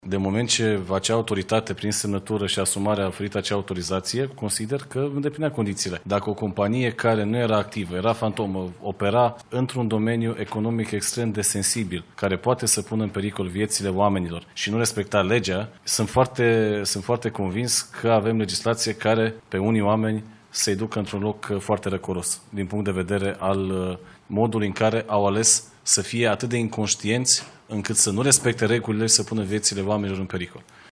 Ministrul Energiei, Bogdan Ivan: „Din moment ce acea autoritate, prin semnătură și asumare, a emis acea autorizație, consider că îndeplinea condițiile”